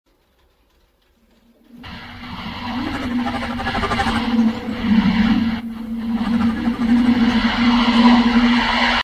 ELECTRONIC PULSES.mp3
Original creative-commons licensed sounds for DJ's and music producers, recorded with high quality studio microphones.
electronic_pulses_fuy.ogg